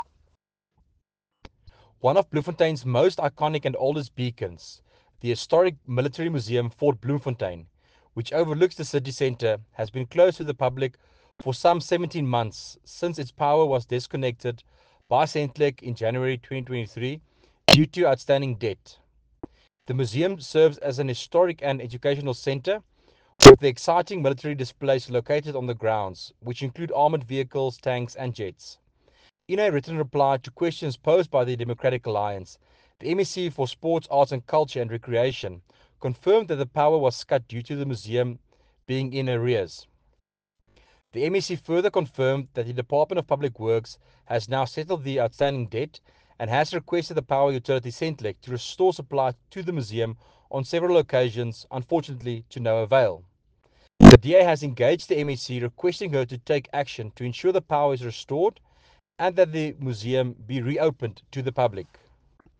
Afrikaans soundbites by Werner Pretorius MPL and images here, here and here